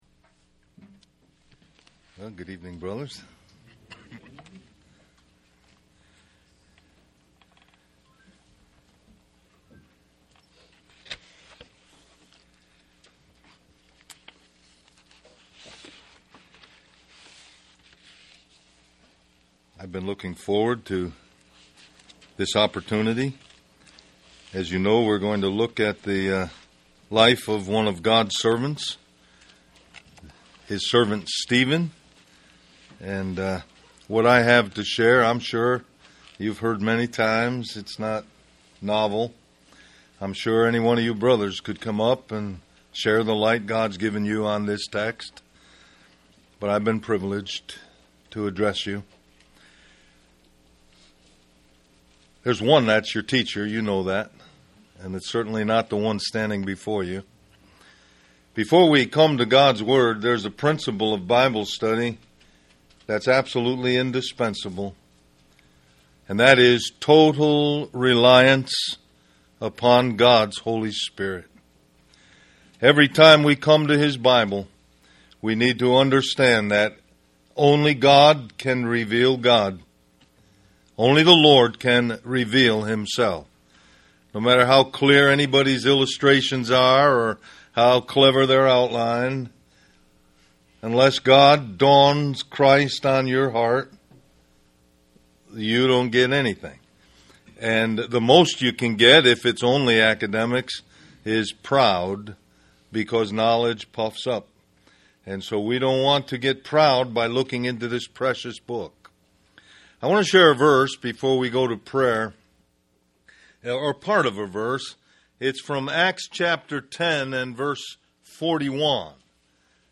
Del Mar Va Mens Retreat 2007 List